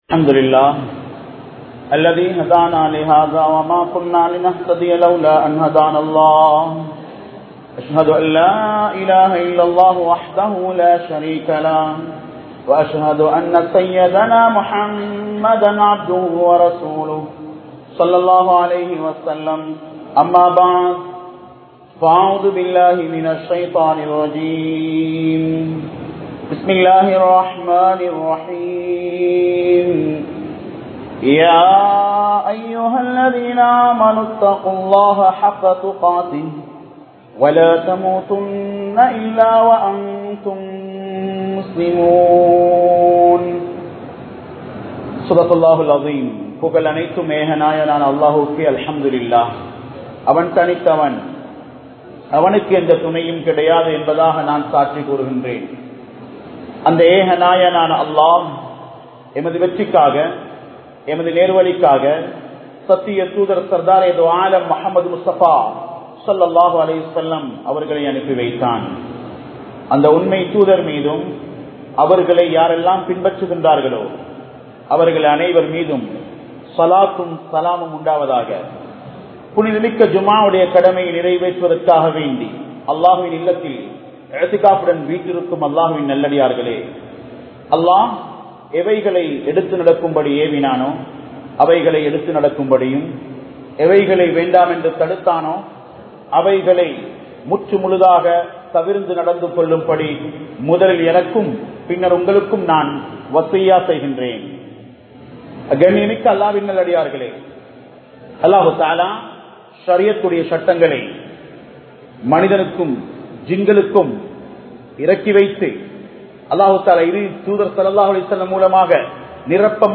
Aniyaayam Seiyathe Aaradi Mannum Kidaikkaathu (அநியாயம் செய்யாதே ஆறடி மண்ணும் கிடைக்காது) | Audio Bayans | All Ceylon Muslim Youth Community | Addalaichenai
Dehiwela, Junction Jumua Masjith